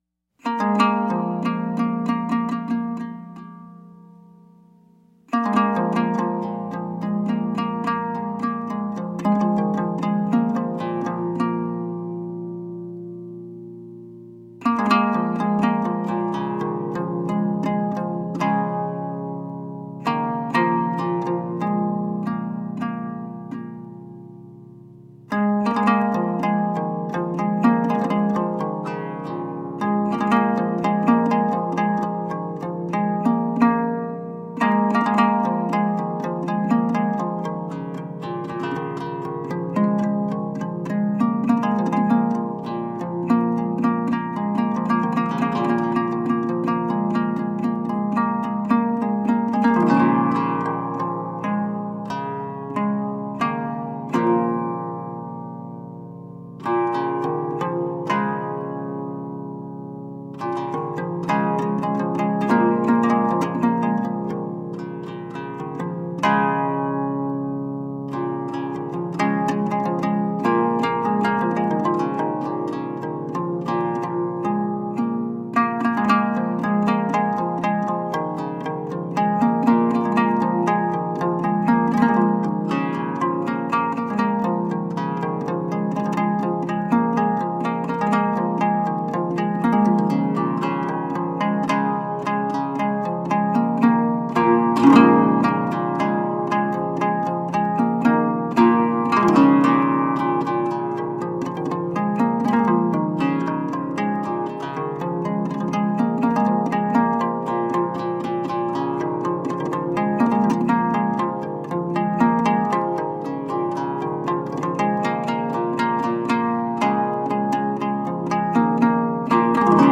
Medieval and middle eastern music.
improvisation for Anglo-Saxon lyre